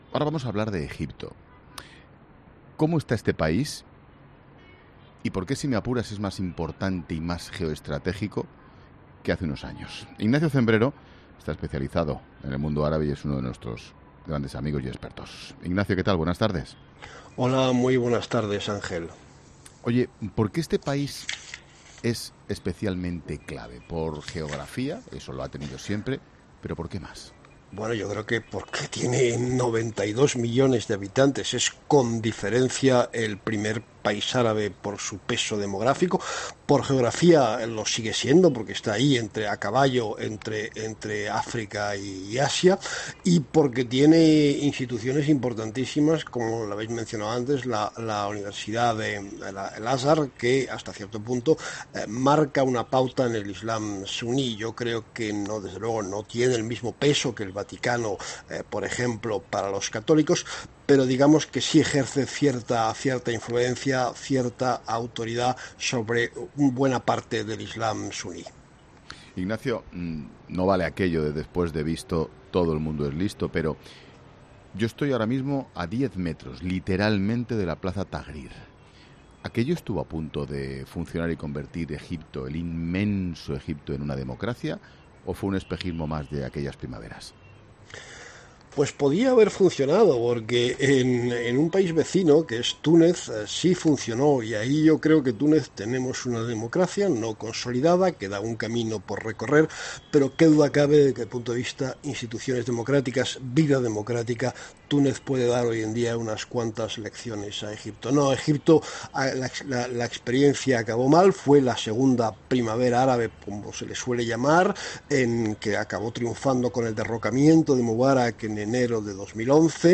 Este jueves se emite 'La Tarde', con todo el equipo dirigido por Ángel Expósito, desde El Cairo (Egipto), ciudad a la que viaja el Papa Francisco este viernes para participar en una Conferencia Internacional para la Paz, auspiciada por la Universidad suní Al-Azhar, donde se reunirá con el gran Imam; el patriarca copto, Teodoro II; y el patriarca ecuménico de Constantinopla, Bartolomé I; quienes harán un llamamiento para frenar todo acto de terrorismo.